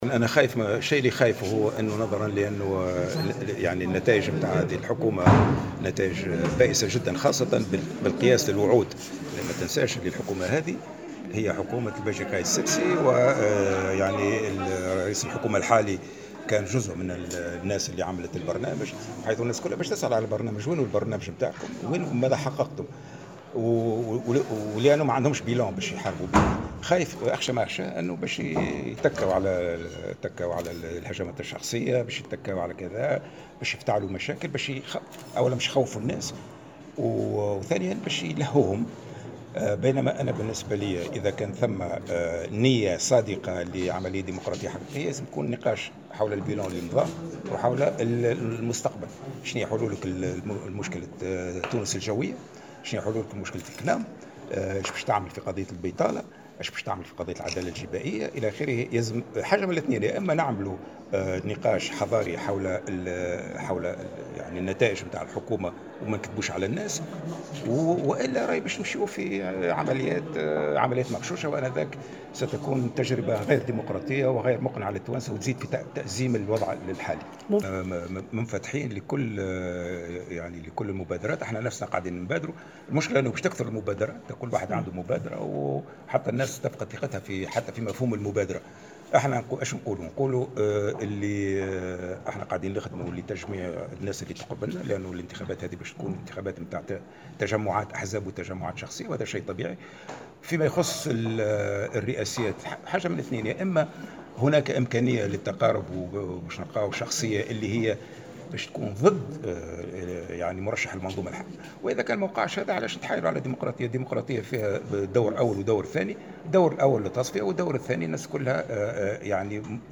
وأضاف في تصريح لمراسلة "الجوهرة أف أم" على هامش اجتماع شعبي نظمه حزبه بجربة ميدون، انه كان تنبأ بهذه الثورة منذ أعوام ولم يتفاجأ بها وانه كان يتوقع ان الموجة الثانية من ثورات الربيع العربي ستأتي لعدة عوامل تتعلق أساسا ببروز أجيال جديدة لا يمكن حكمها بآليات قديمة، مشيدا بالحراك الحالي في الجزائر.